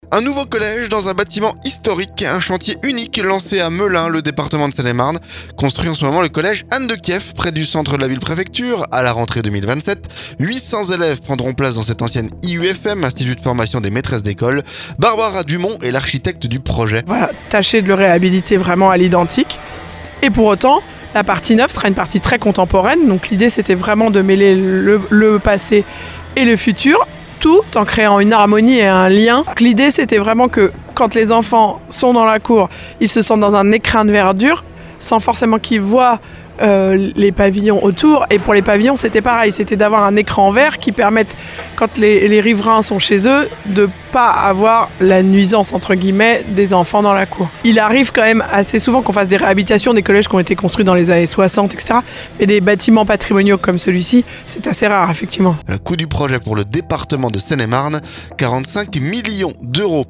MELUN - Reportage au cœur des travaux du futur collège Anne de Kiev